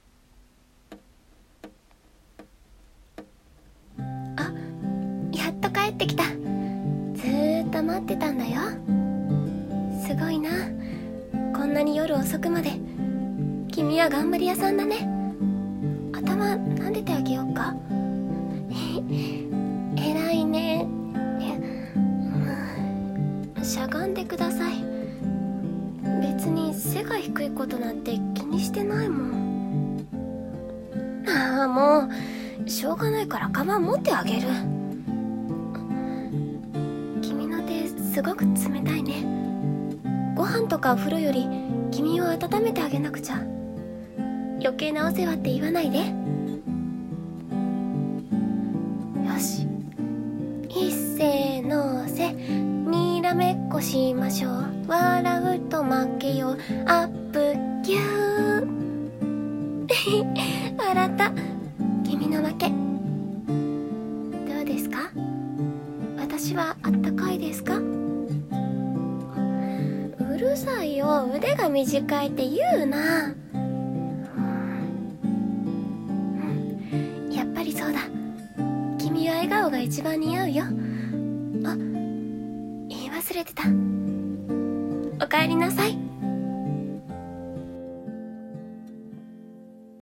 おかえり【声劇】